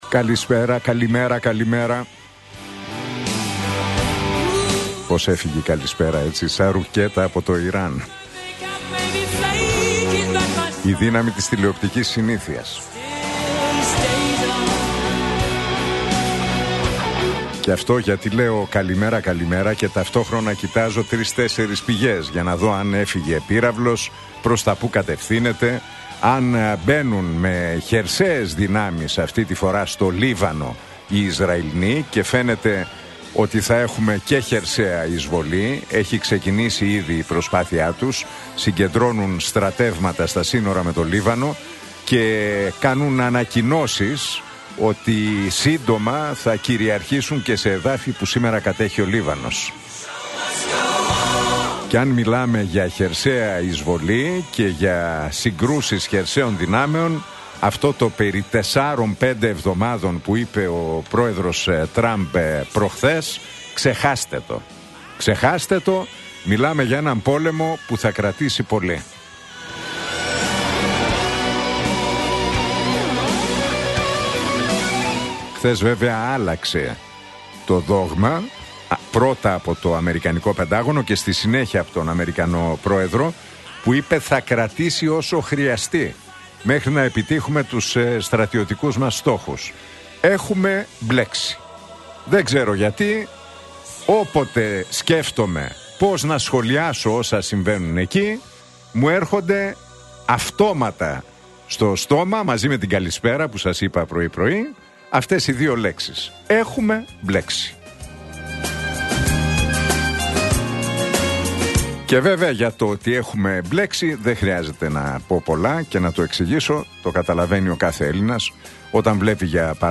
Ακούστε το σχόλιο του Νίκου Χατζηνικολάου στον ραδιοφωνικό σταθμό Realfm 97,8, την Τρίτη 3 Μαρτίου 2026.